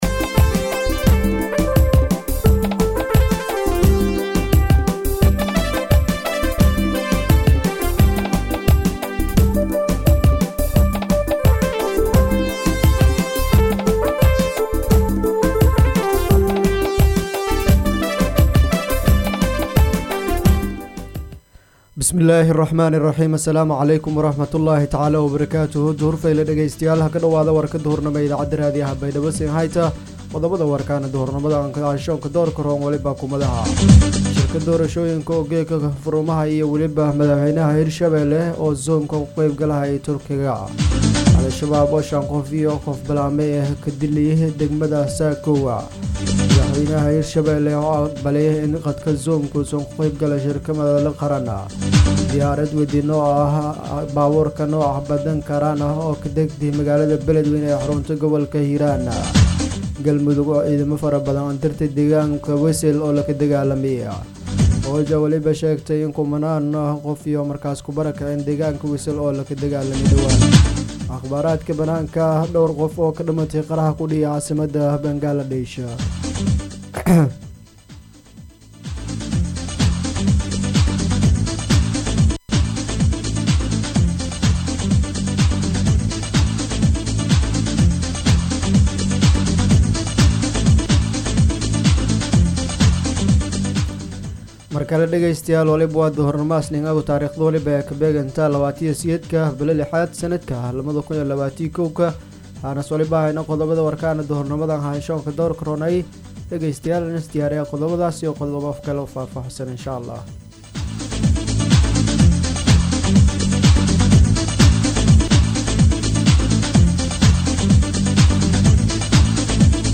BAYDHABO–BMC:–Dhageystayaasha Radio Baidoa ee ku xiran Website-ka Idaacada Waxaan halkaan ugu soo gudbineynaa Warka maanta ee ka baxay Radio Baidoa.